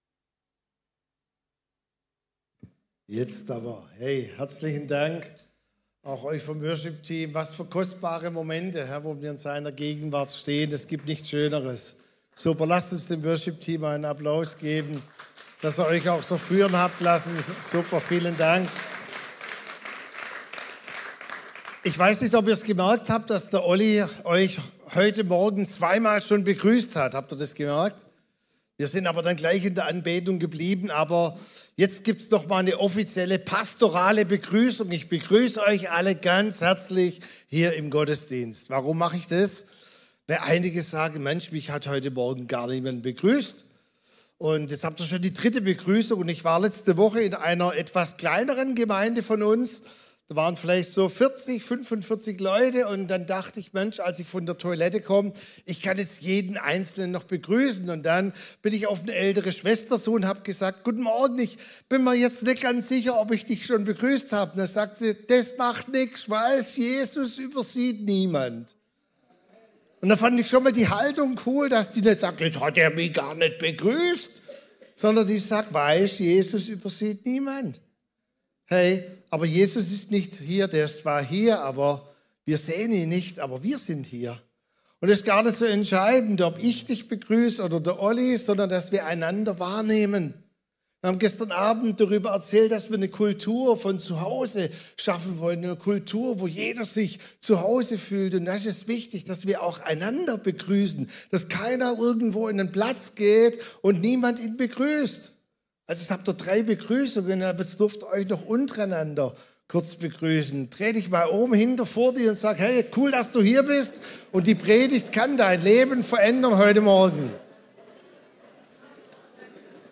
Sonntagspredigten
Wöchentliche Predigten des Christlichen Gemeindezentrums Albershausen